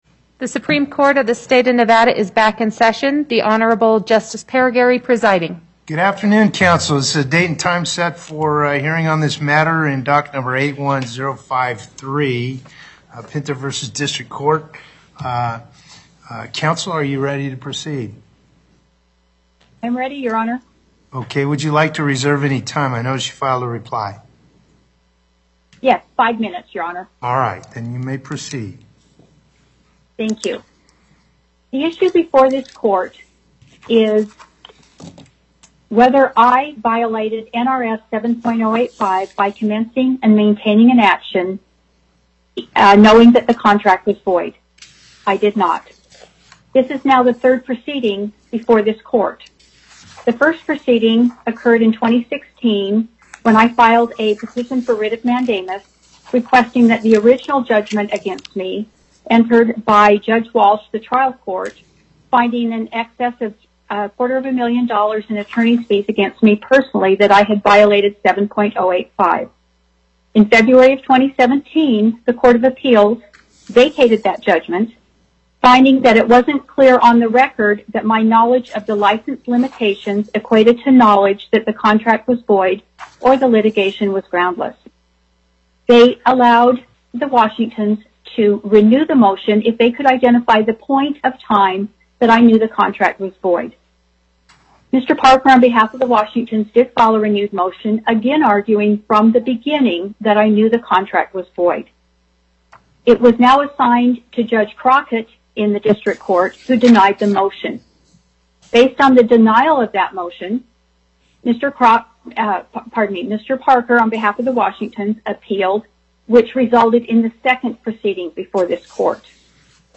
Location: Carson City Before the Northern Nevada Panel, Justice Parraguirre Presiding